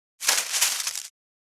600コンビニ袋,ゴミ袋,スーパーの袋,袋,買い出しの音,ゴミ出しの音,袋を運ぶ音,
効果音